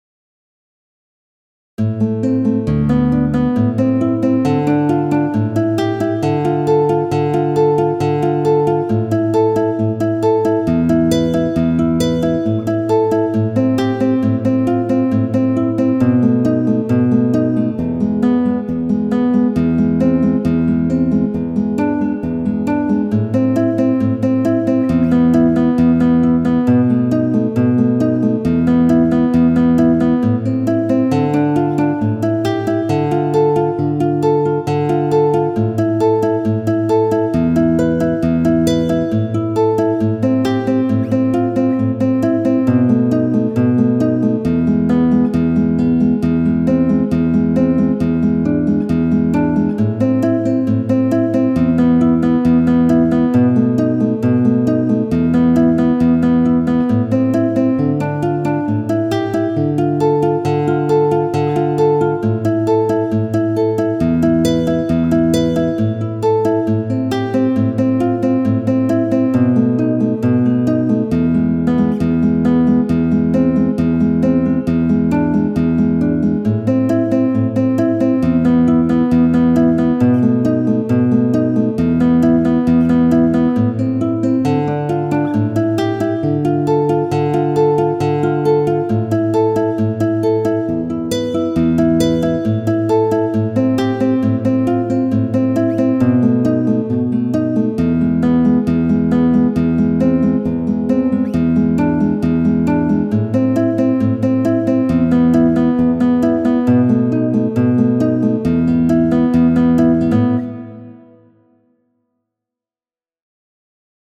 Wer hat ein gutes Classic Gitarren VSTi? Midi to Audio gewünscht
Hier noch mal ein kurzer Ausschnitt ohne "Rutscher".